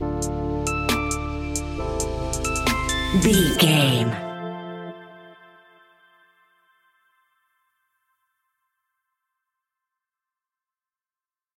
Chilled Hip Hop Ballad Music Stinger.
Aeolian/Minor
hip hop instrumentals
laid back
groove
hip hop drums
hip hop synths
piano
hip hop pads